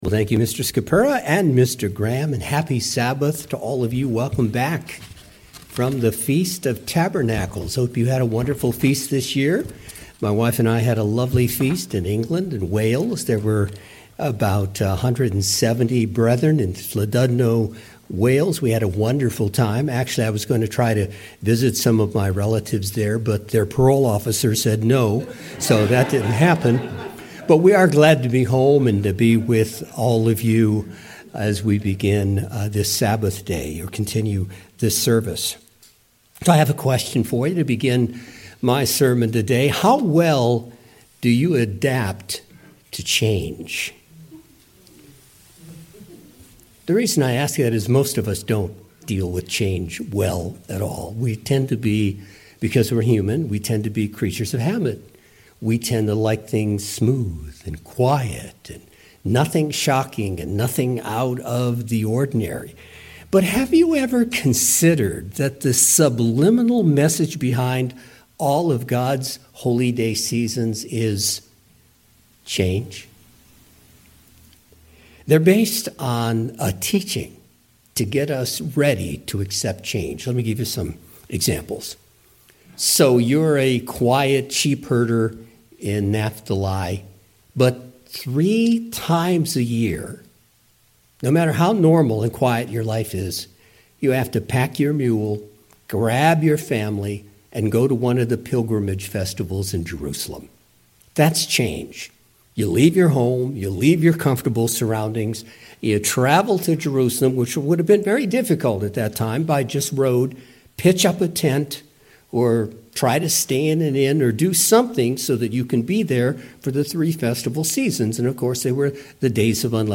In this Sermon, I would like to discuss the issue of accepting change.